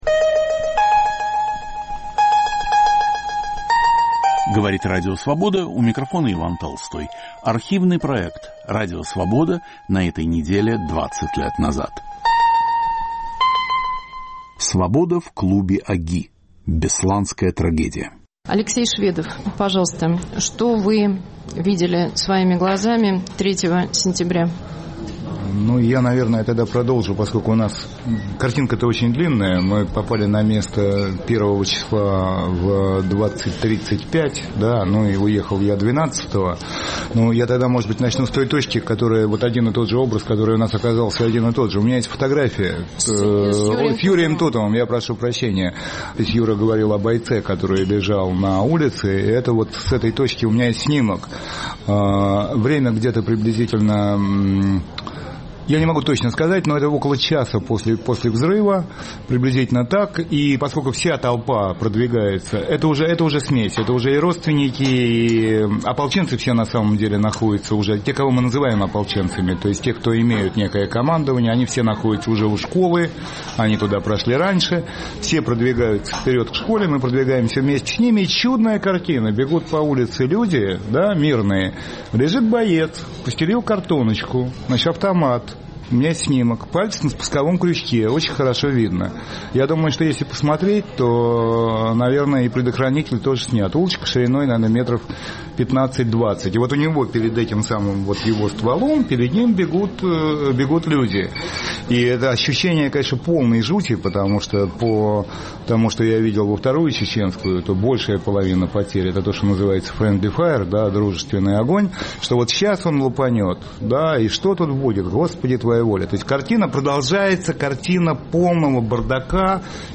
"Свобода" в клубе ОГИ. Бесланская трагедия